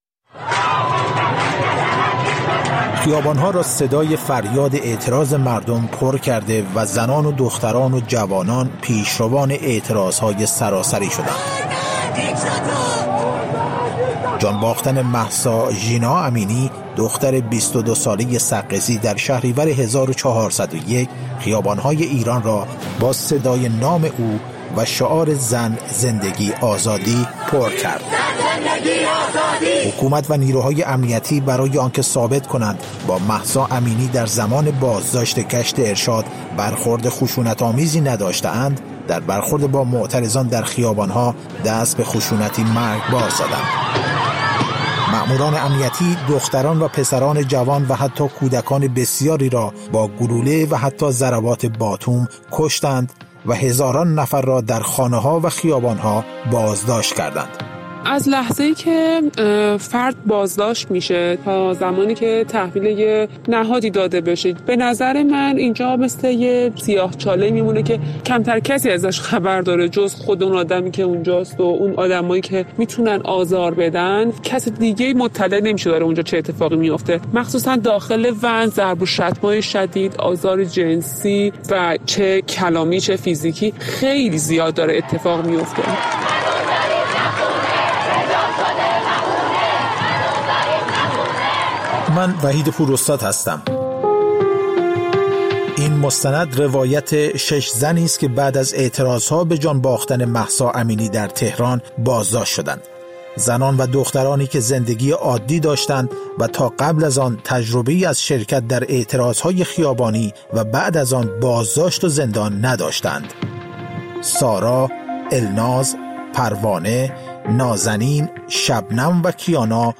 مستند رادیویی: سیاه‌چاله؛ روایت زنان از و‌ن‌های پلیس